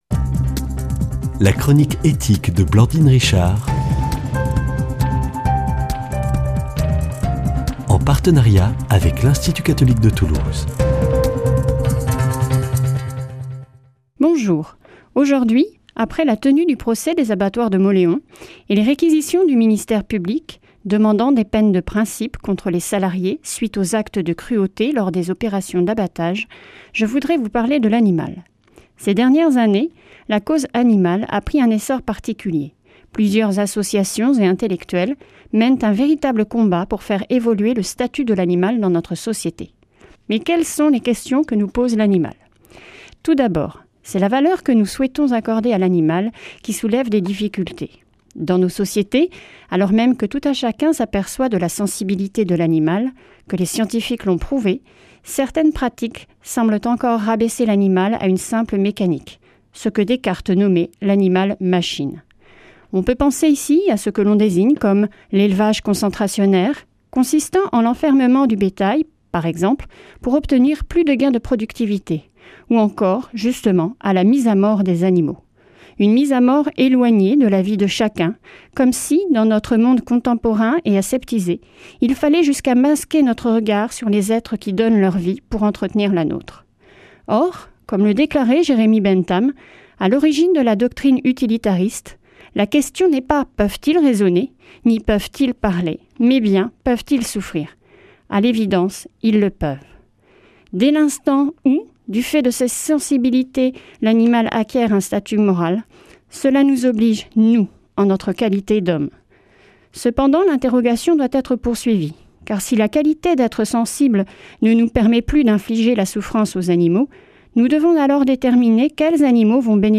Chronique Ethique